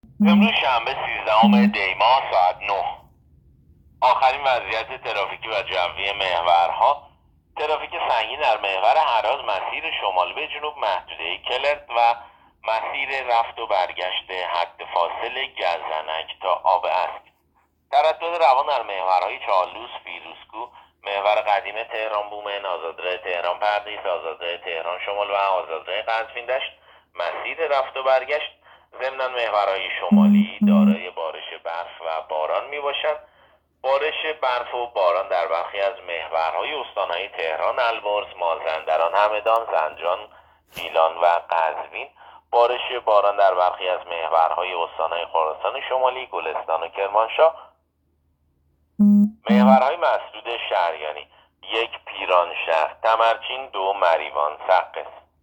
گزارش رادیو اینترنتی از آخرین وضعیت ترافیکی جاده‌ها ساعت ۹ سیزدهم دی؛